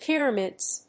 /ˈpɪrʌmɪdz(米国英語)/